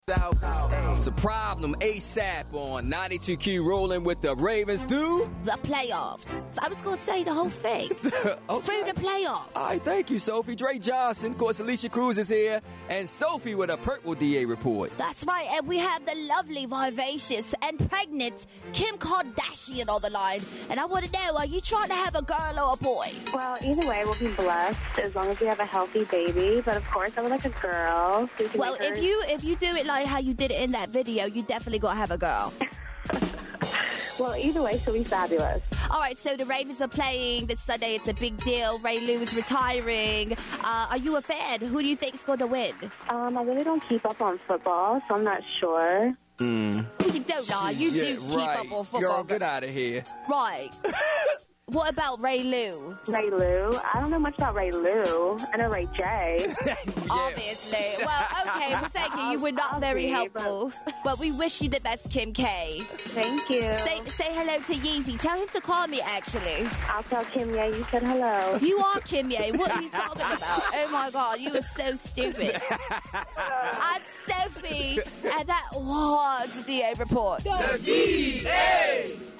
We caught up with Kim Kardashian and got her to speak on her pregnancy, the Ravens and Ray Lew.